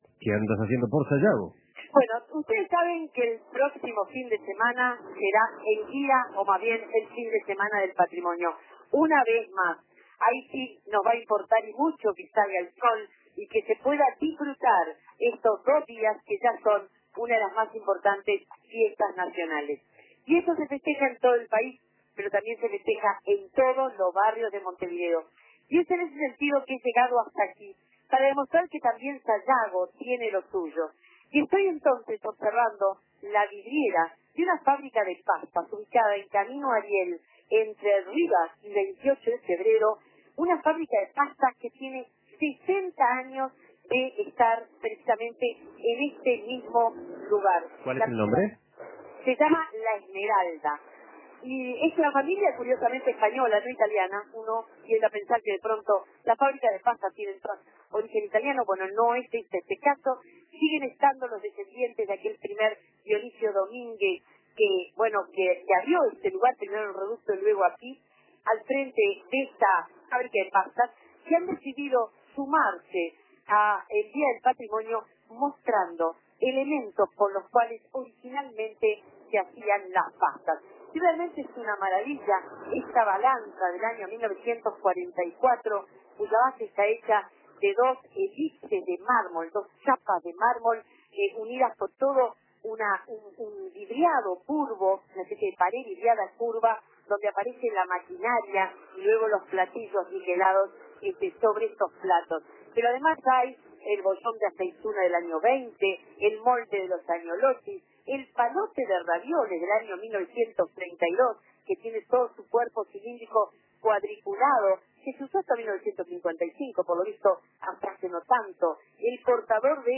Fábrica de pastas de Sayago decidió sumarse al Día del Patrimonio mostrando elementos que se usaban décadas atrás para fabricar el producto. Móvil